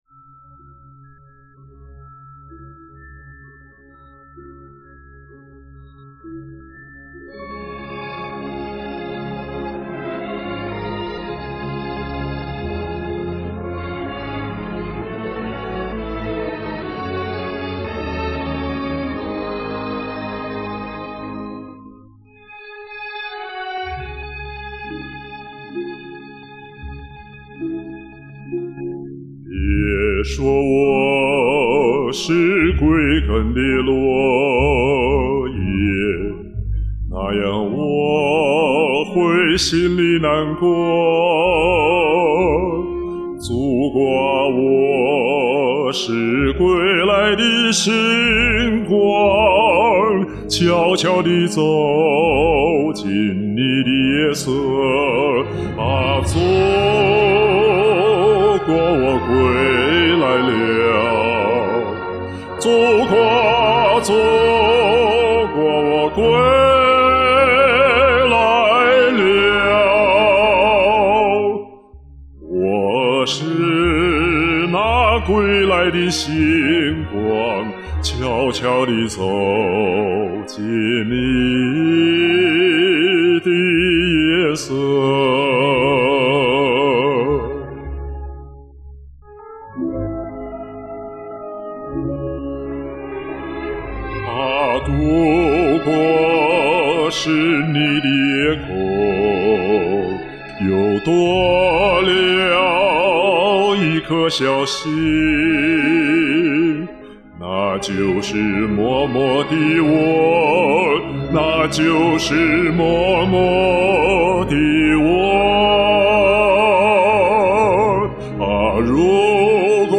大气恢弘
歌我唱过，知道不太好唱，你宽厚的低音演绎十分漂亮，为你精彩演唱热烈鼓掌！